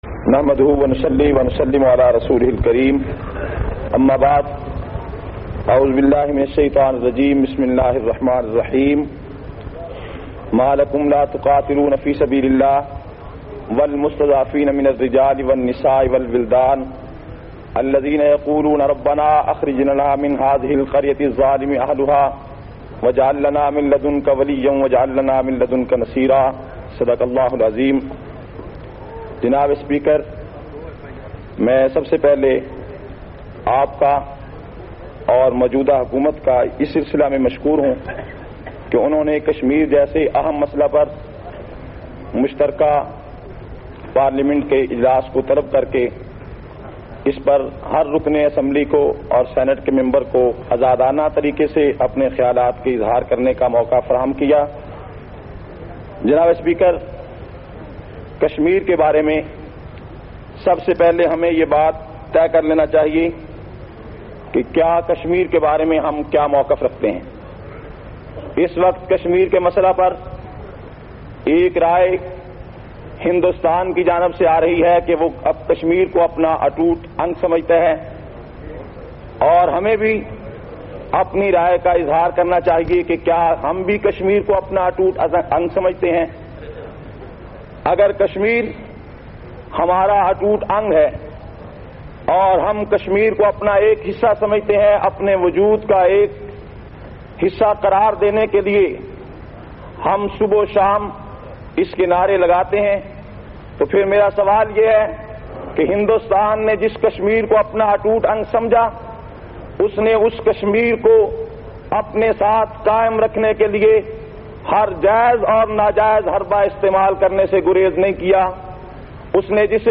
71- Masla-e-kashmir-assambly-say-khitab.mp3